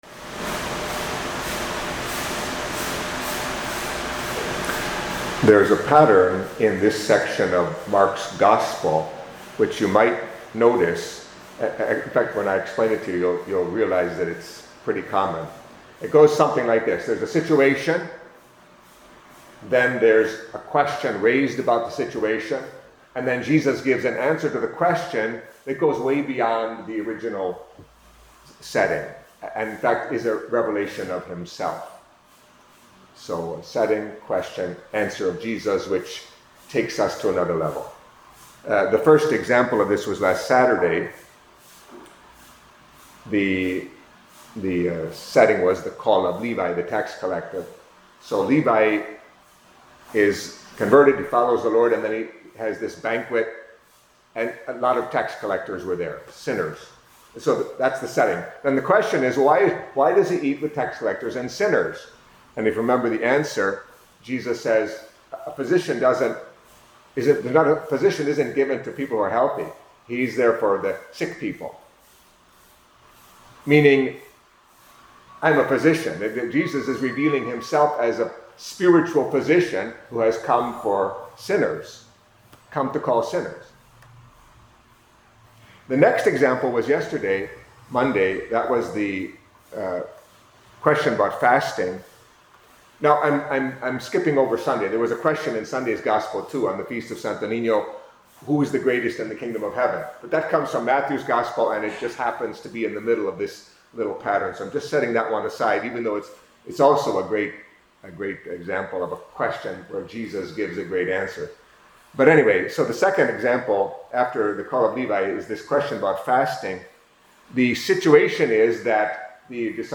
Catholic Mass homily for Tuesday of the Second Week in Ordinary Time